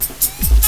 FXLOOP 01 -R.wav